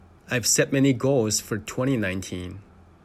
2019-sentence-pronunciation.mp3